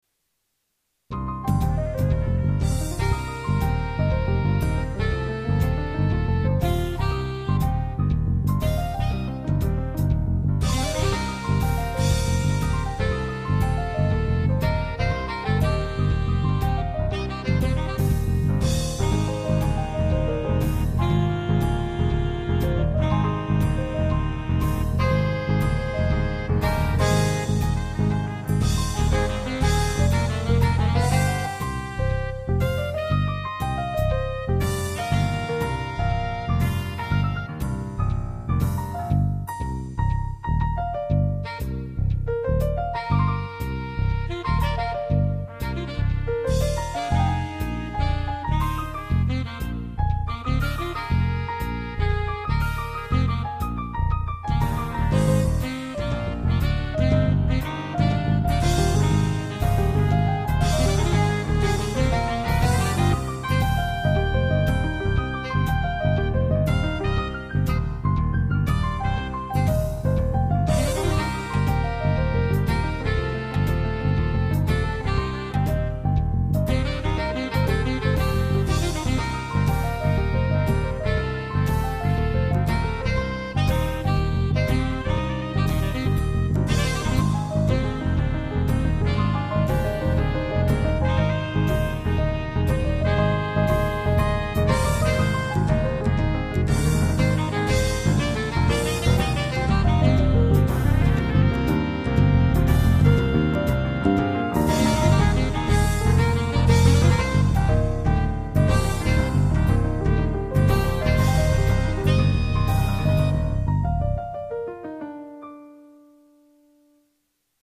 音量は大きめで録音してあるので、ご使用の際はゲインを絞ったり用途に合わせて下さい。
サックスメインのジャズ。